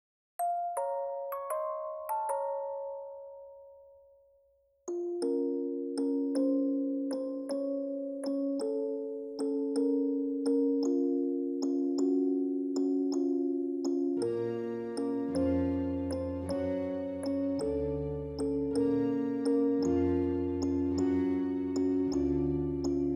improved gameover bgm